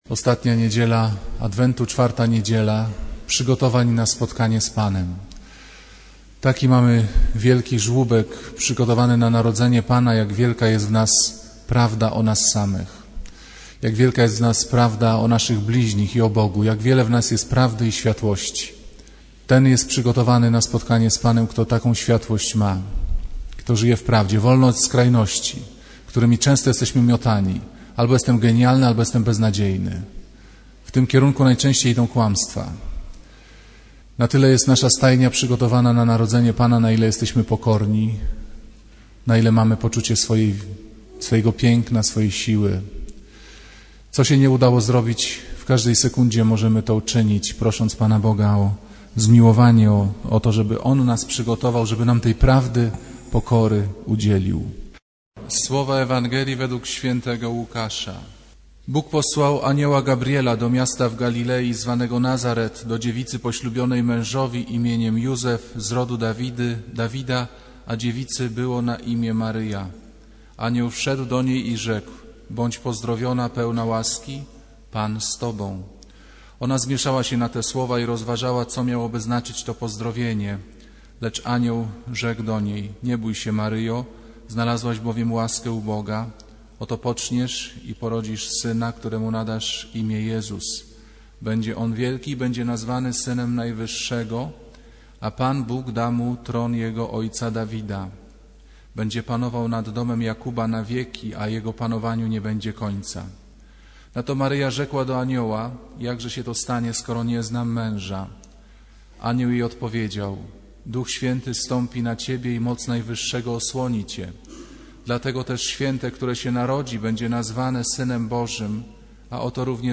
Kazanie z 21 grudnia 2008r.